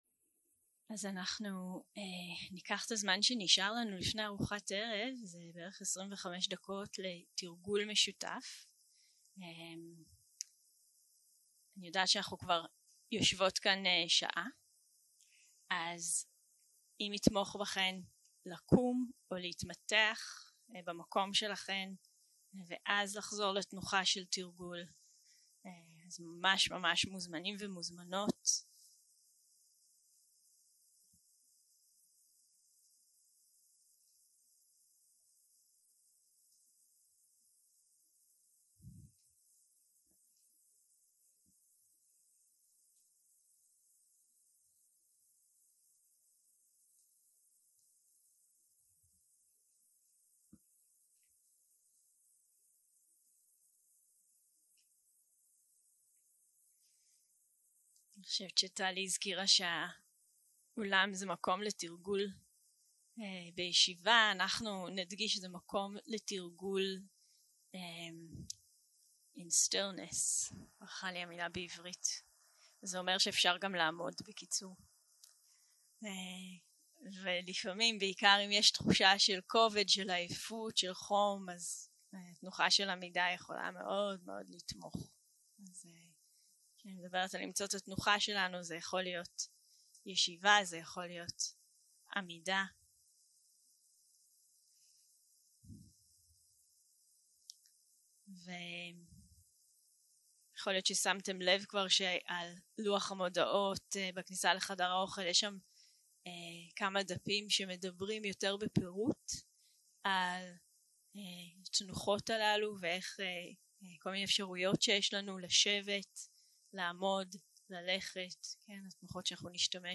יום 1 - הקלטה 1 - ערב - מדיטציה מונחית
Guided meditation